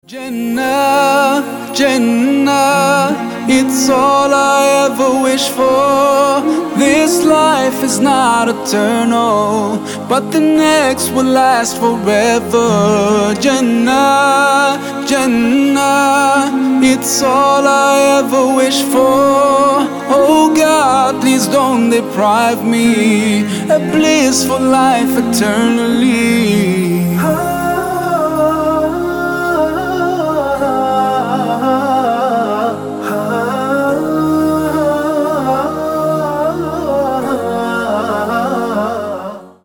Поп Музыка
спокойные # грустные